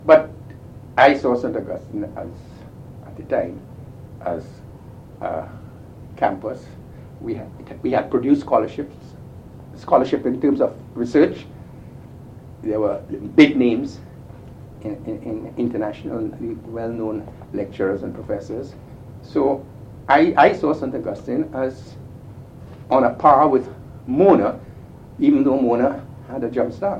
1 audio cassette